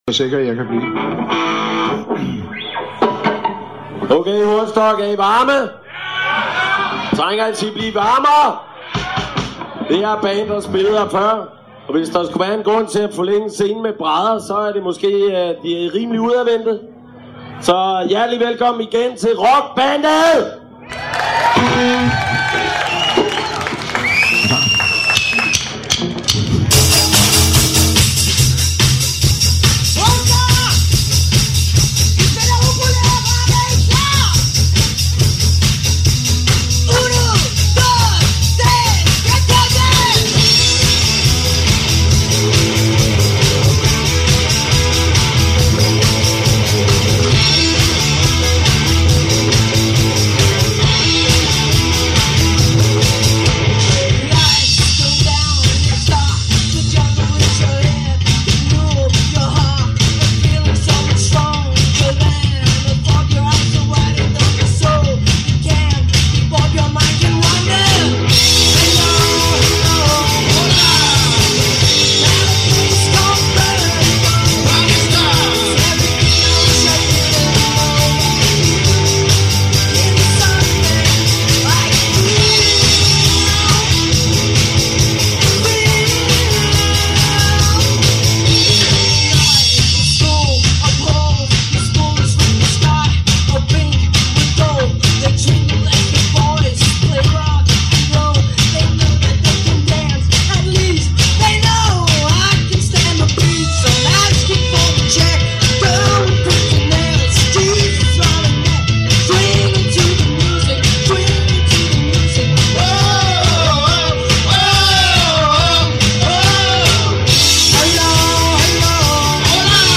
Sang
Trommer
Guitarer
Live Hornsrock Festival